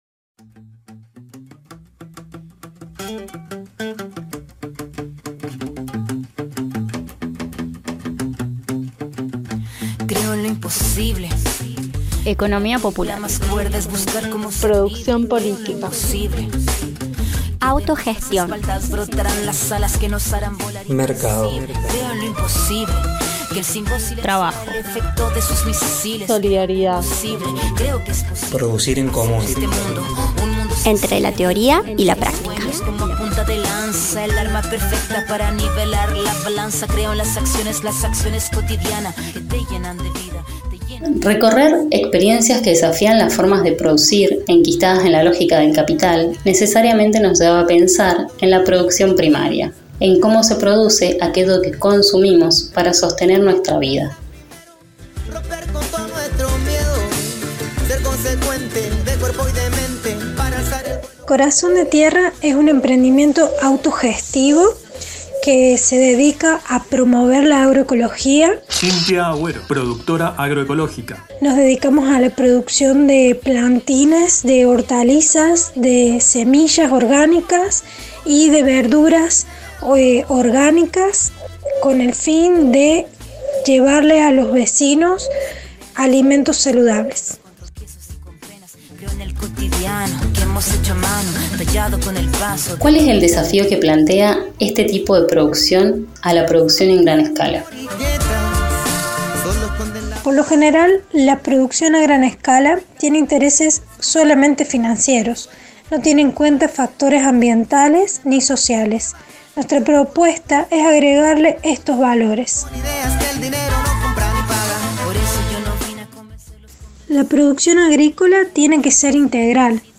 Presentamos, así, los cruces, las voces y los sonidos que van construyendo esta particular polifonía…